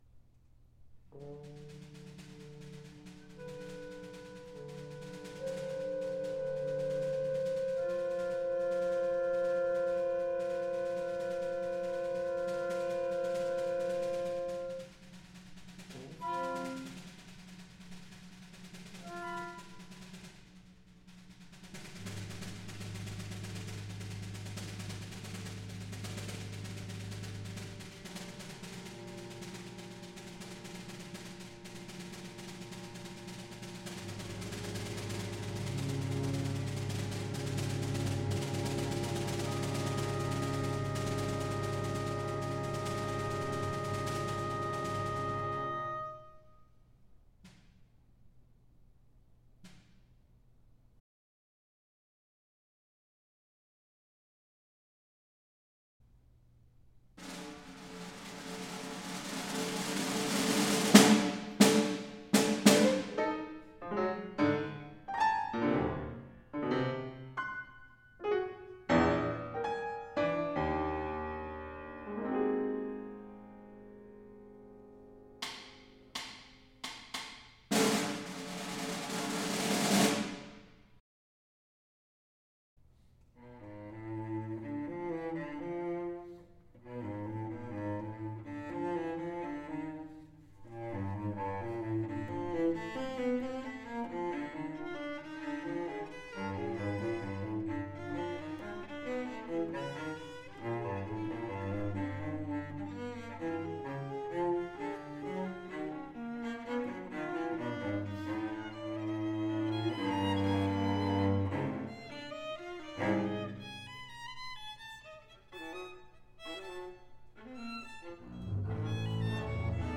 Large Ensemble Music (10+ musicians)
What story down there awaits its end? (2024) for large chamber ensemble